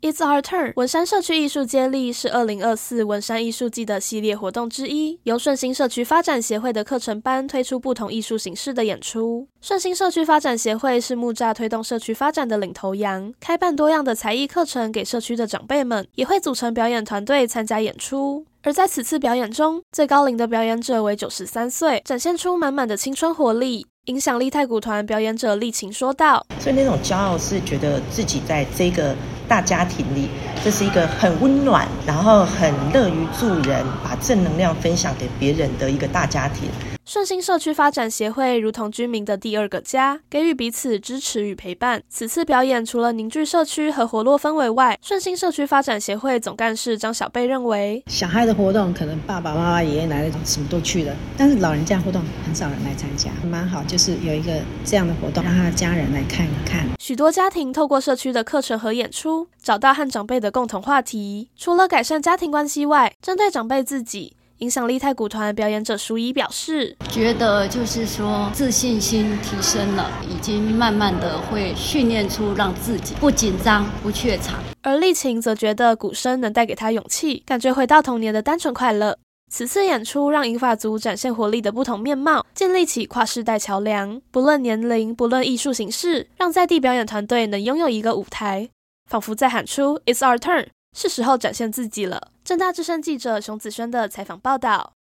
政大之聲實習廣播電台-大台北重點新聞